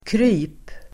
Uttal: [kry:p]